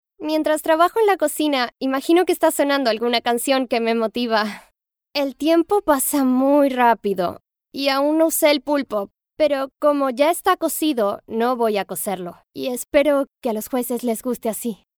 Feminino
Espanhol - América Latina Neutro
COMEDIA
Voz Jovem 00:15